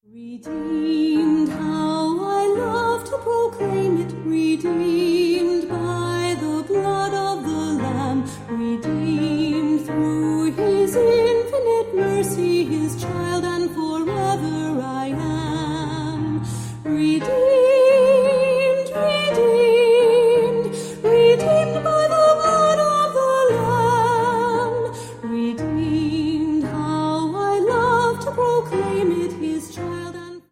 mit leichter instrumentaler begleitung
• Sachgebiet: Praise & Worship